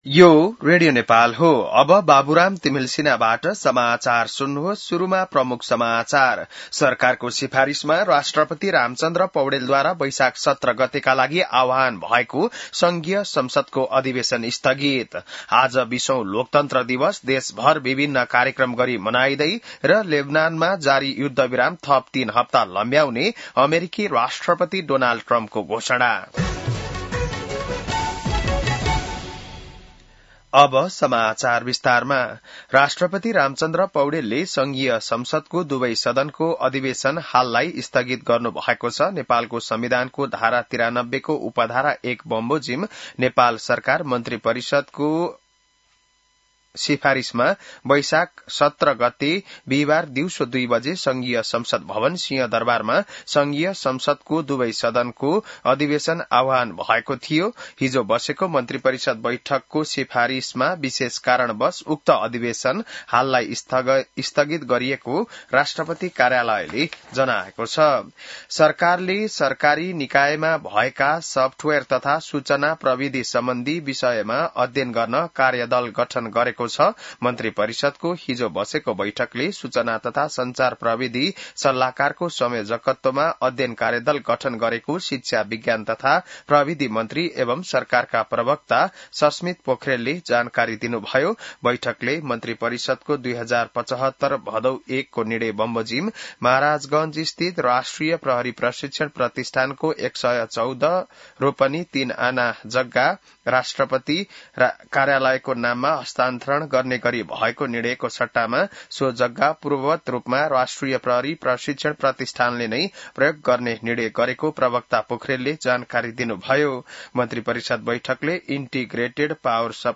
बिहान ९ बजेको नेपाली समाचार : ११ वैशाख , २०८३